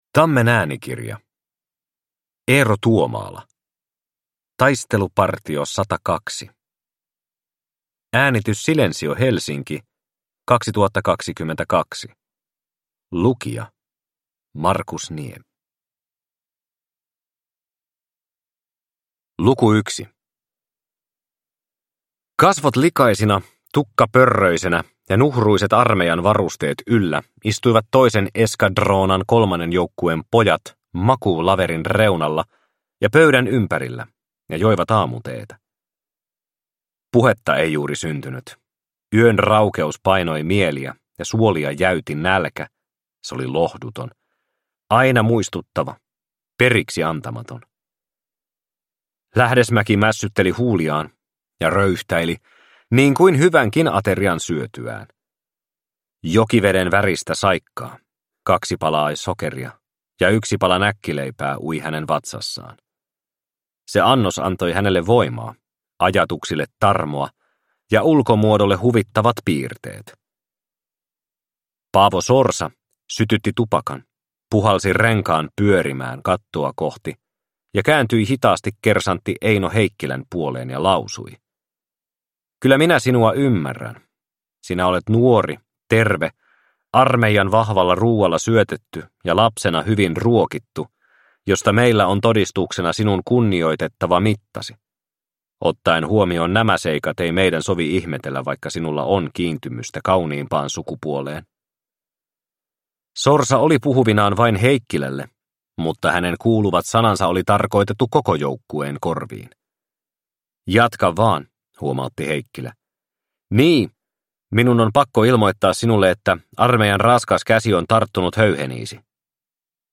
Taistelupartio 102 – Ljudbok – Laddas ner